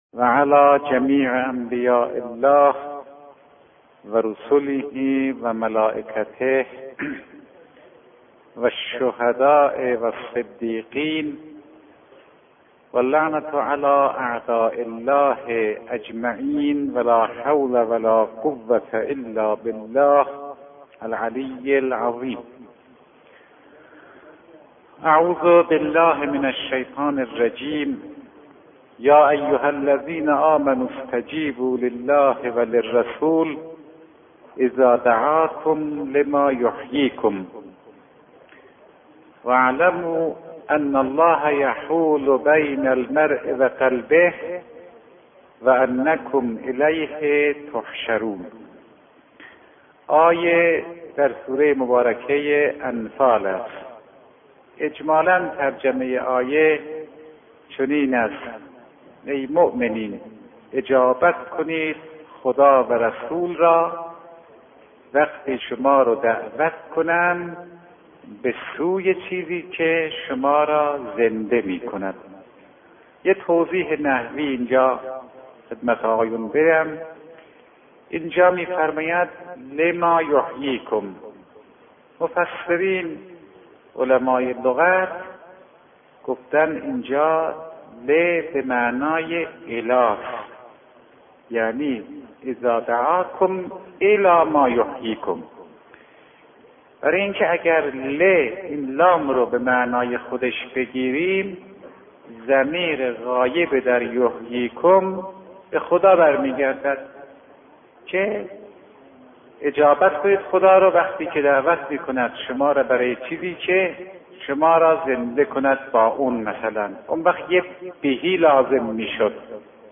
مرحوم آیت الله فاطمی نیا در این سخنرانی به تفسیر آیه‌ای از سوره انفال، ارتباط باطن انسان با هدایت الهی و اهمیت دعا و خودسازی در ماه رمضان پرداخته‌اند.
این فایل صوتی، گزیده‌ای از سخنرانی مرحوم آیت‌الله فاطمی‌نیا است که به تفسیر آیات قرآنی در باب اخلاق و تربیت نفس می‌پردازد. ایشان با استناد به آیه‌ای از سوره انفال، لزوم اجابت دعوت خدا و رسول را برای حیات معنوی انسان شرح می‌دهند.